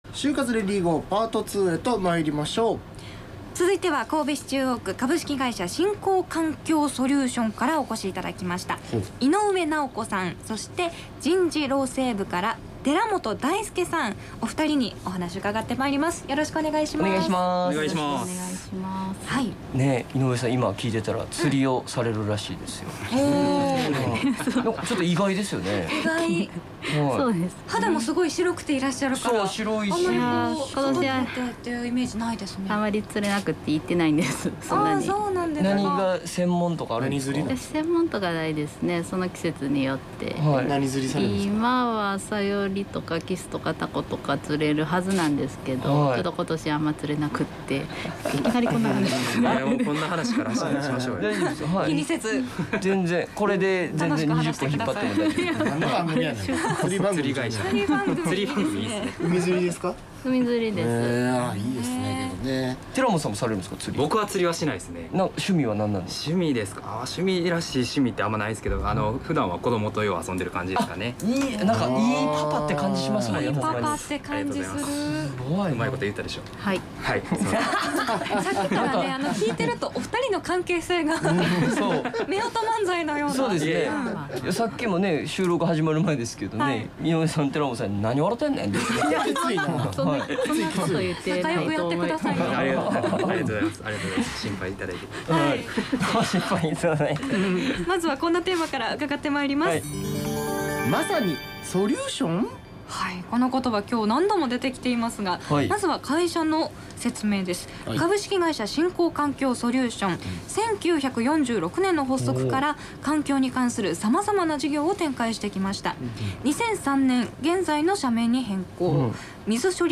先輩社会人ロールモデルが、ラジオ番組でその実体験を語った。
【放送音声】『ネイビーズアフロのレディGO！HYOGO』2021年8月20日放送回より